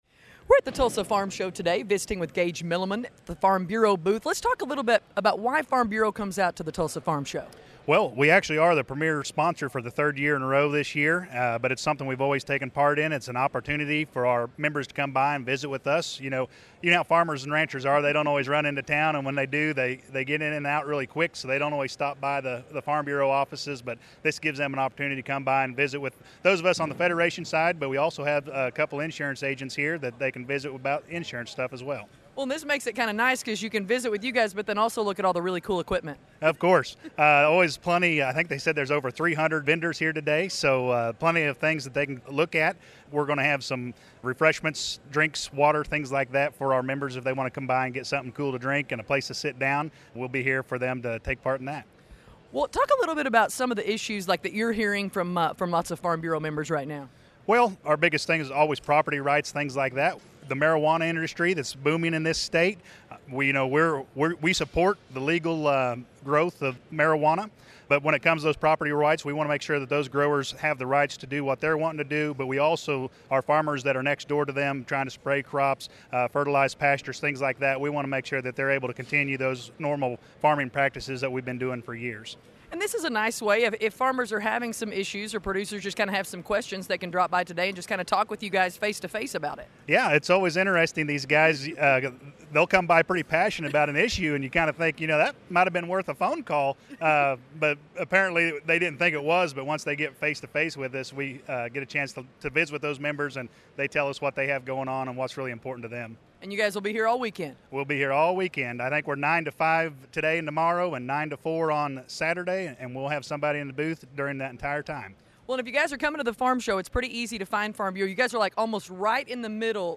Stop By the Oklahoma Farm Bureau Booth at the Tulsa Farm Show It is the opening day of the Tulsa Farm Show!